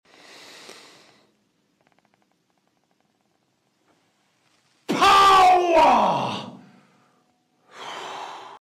Jeremy Fragrance schreit "POWER!" Meme sound effects free download